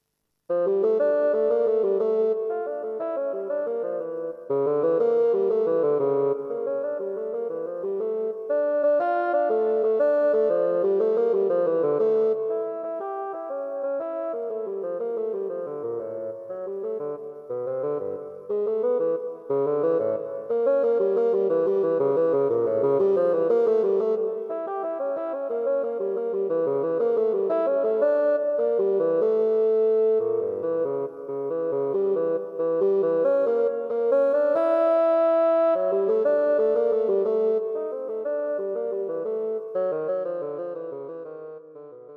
Bassoon Solos
A set of four unaccompanied Bassoon solos.